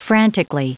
Prononciation du mot frantically en anglais (fichier audio)
Vous êtes ici : Cours d'anglais > Outils | Audio/Vidéo > Lire un mot à haute voix > Lire le mot frantically